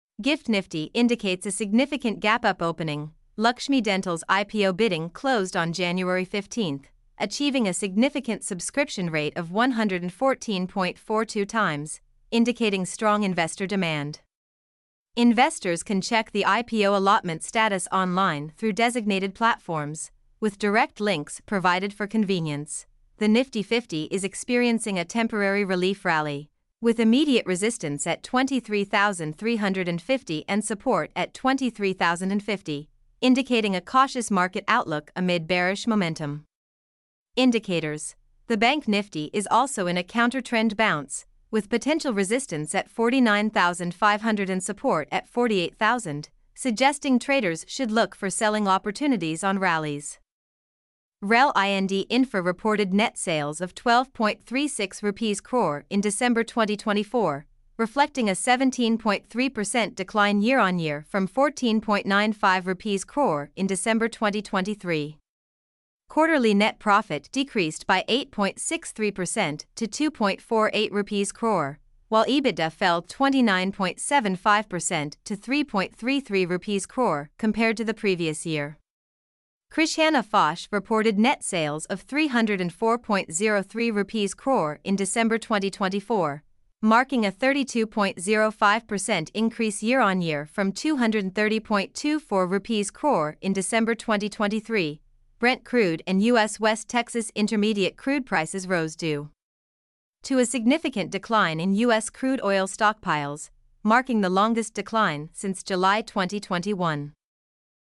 mp3-output-ttsfreedotcom-47.mp3